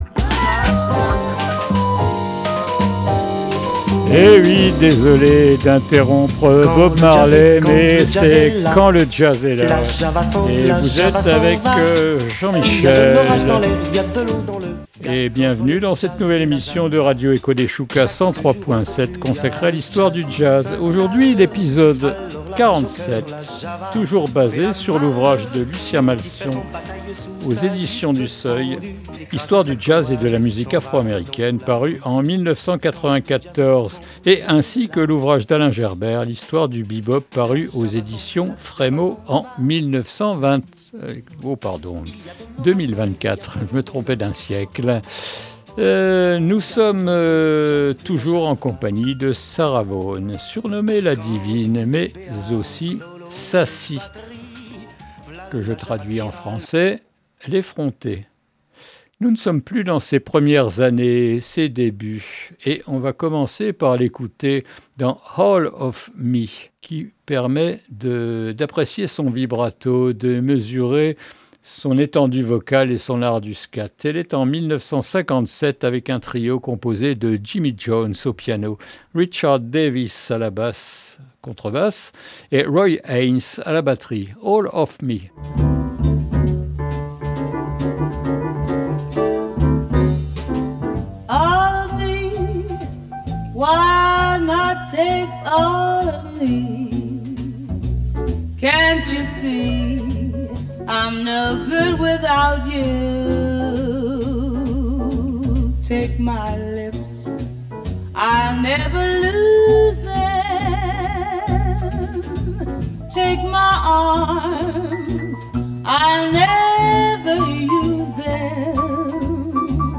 Quand le jazz est là est une nouvelle émission consacrée à l’histoire du jazz.